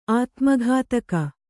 ♪ ātmaghātaka